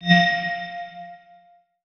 Sci Fi Stinger 4.wav